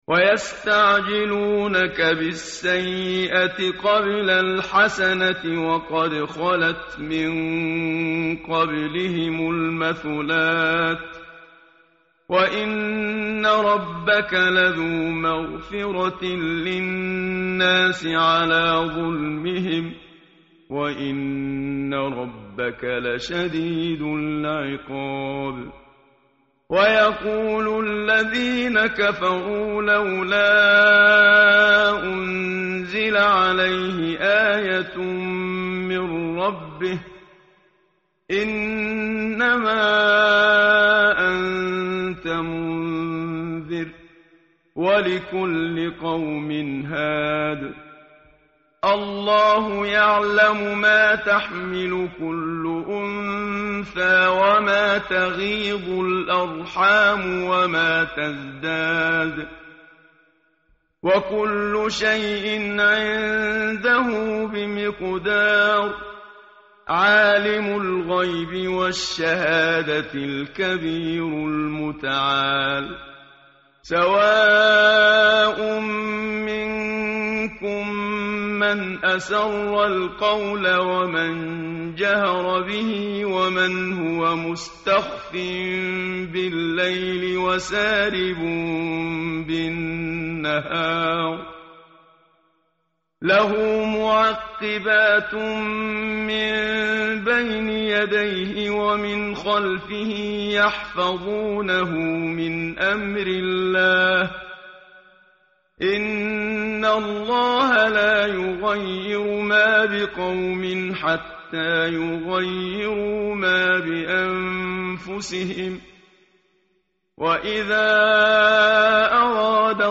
tartil_menshavi_page_250.mp3